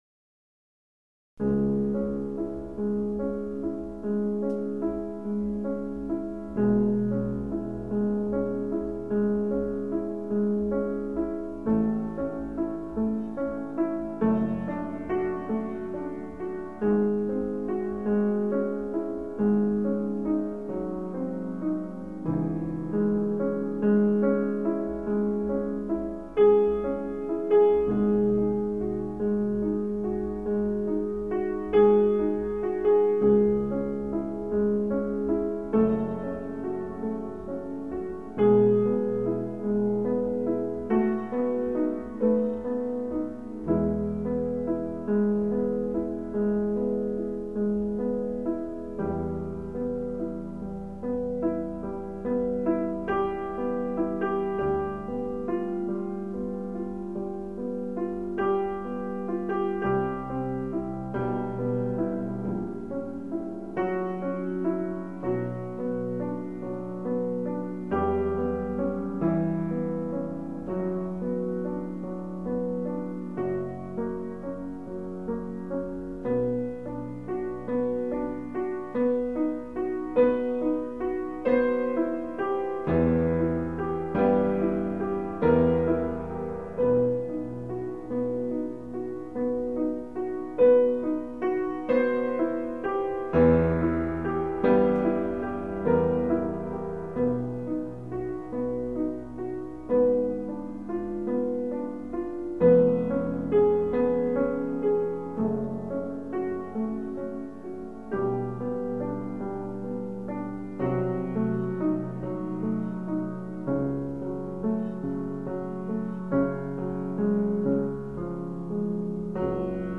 Professional Grand
Moonlight Sonata played on the 1873 Steinway